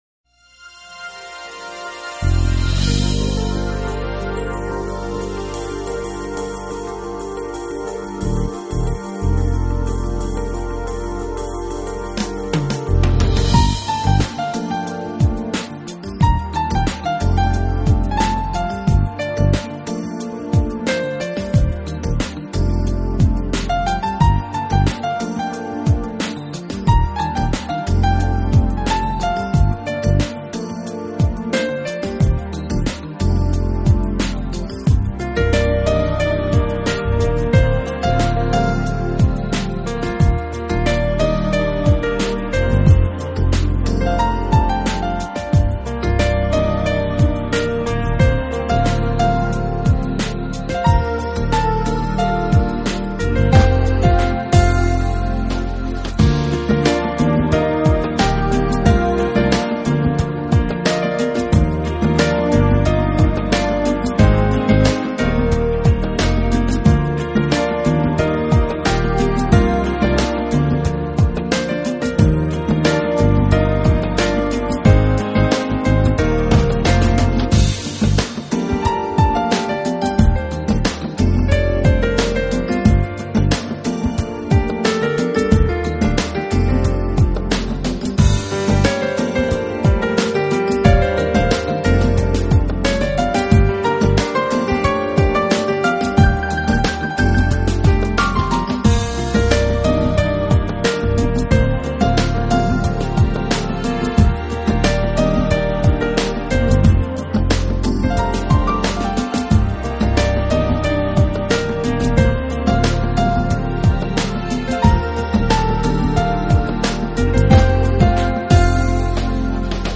Smooth Jazz